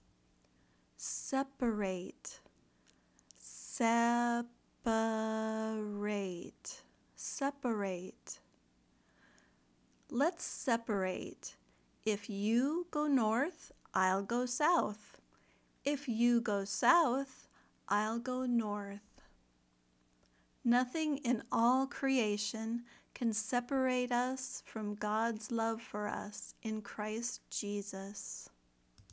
/ˈse pə reɪt/  (verb)